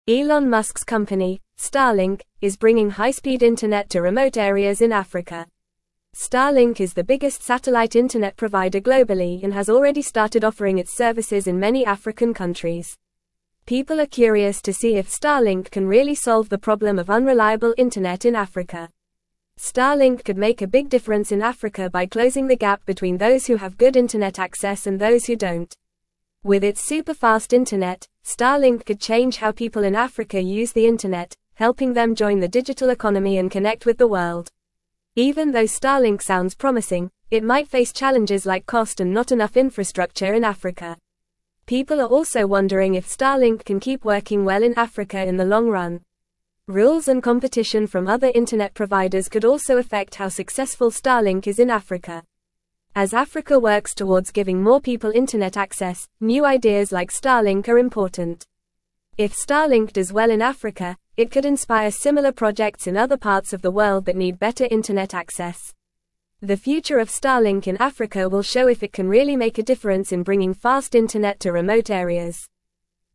Fast